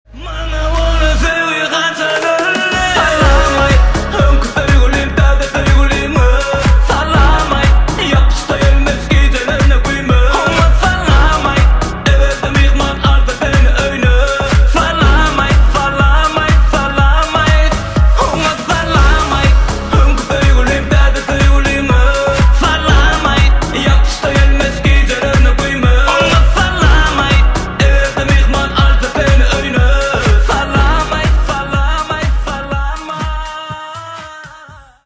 восточные